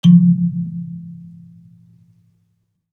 kalimba_bass-F2-mf.wav